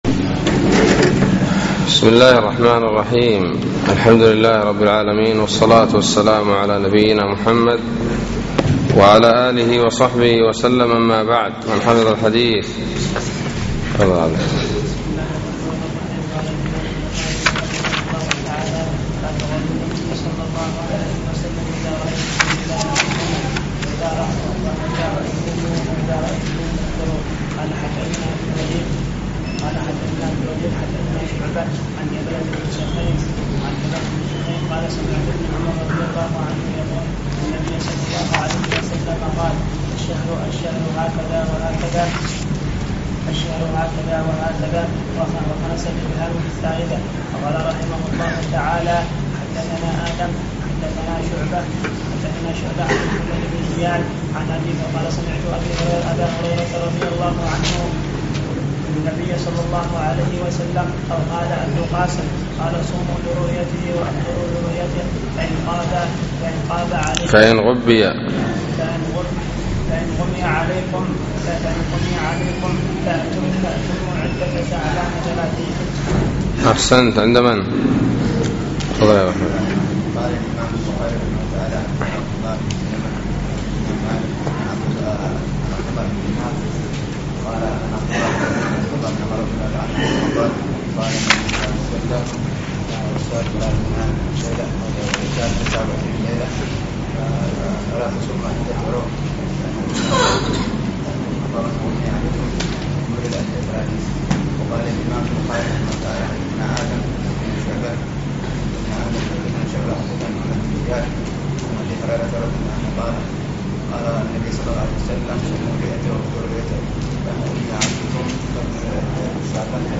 الدرس العاشر : باب شهرا عيد لا ينقصان